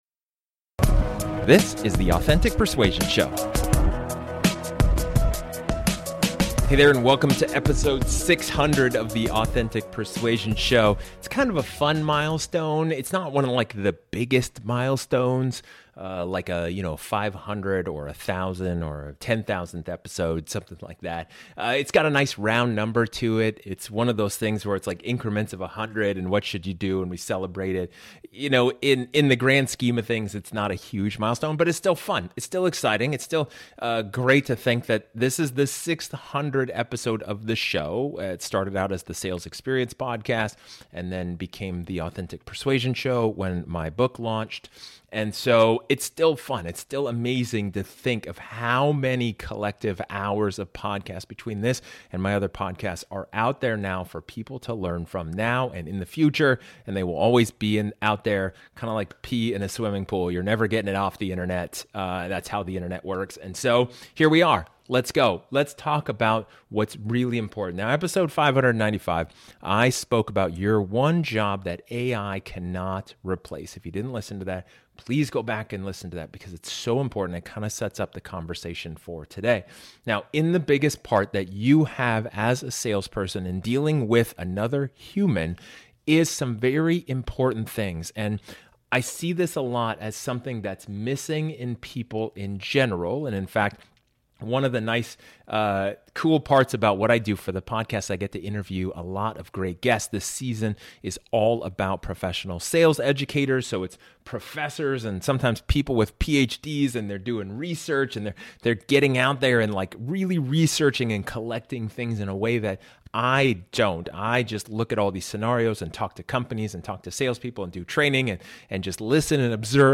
In this solo episode, I talk about the one superpower you need to have in sales.